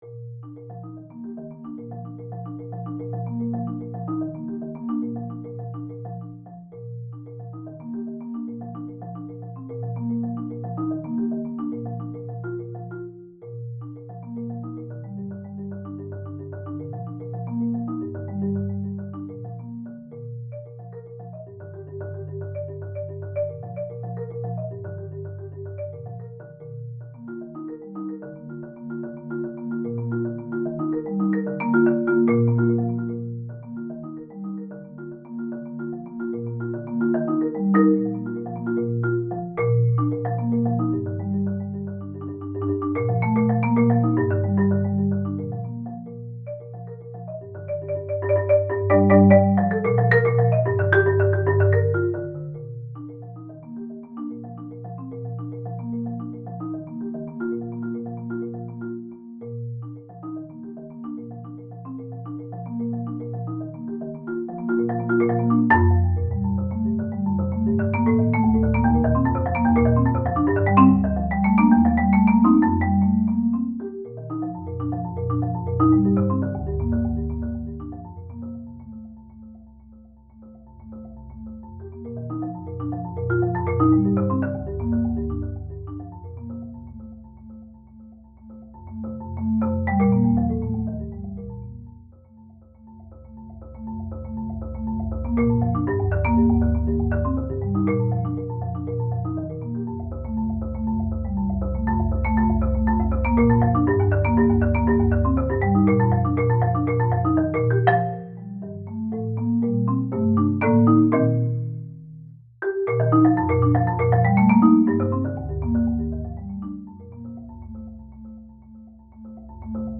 • Instrumentation: Solo marimba (Low C)